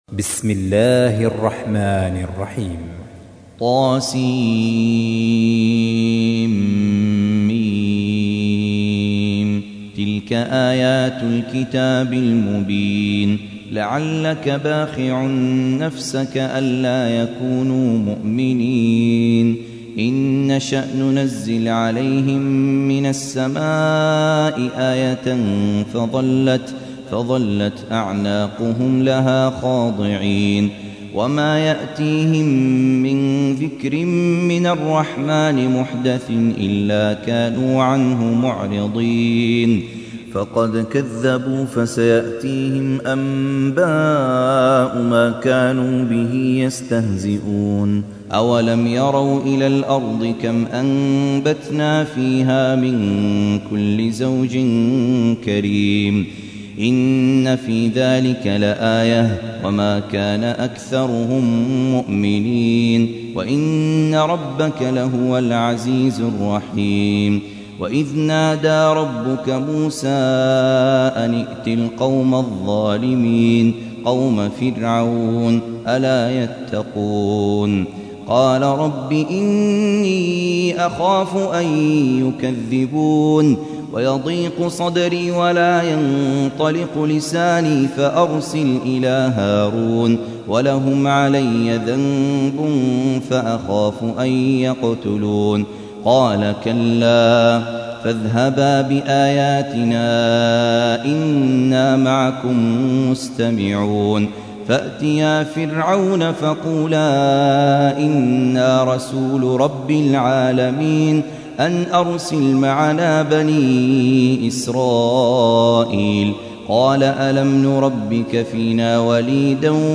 تحميل : 26. سورة الشعراء / القارئ خالد عبد الكافي / القرآن الكريم / موقع يا حسين